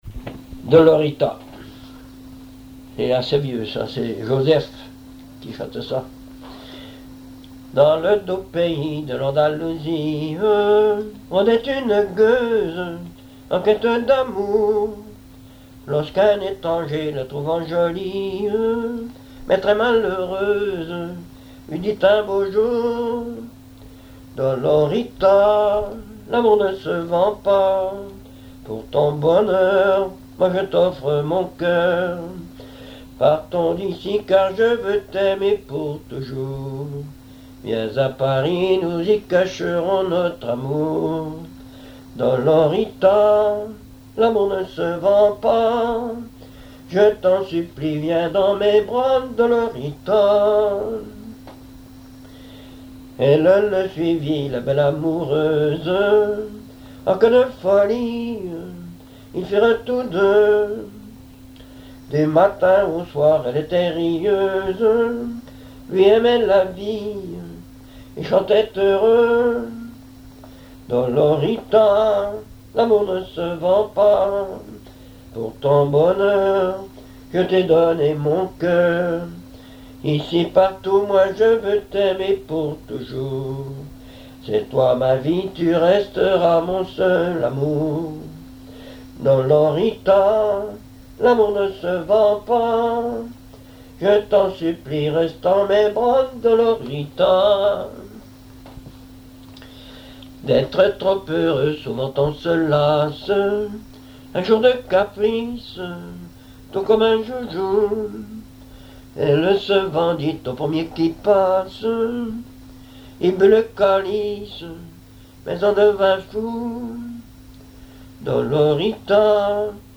Genre strophique
chansons populaires et histoires drôles
Pièce musicale inédite